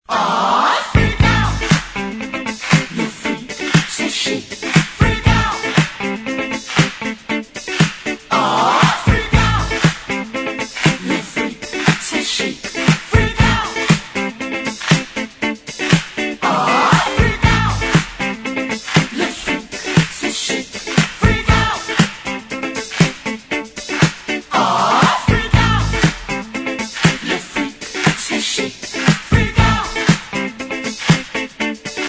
Suonerie Dance